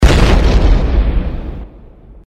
EXPLOSIONEFECTOS DE SONIDO GRAN EXPLOSION
Ambient sound effects
explosionefectos_de_sonido_gran_explosion.mp3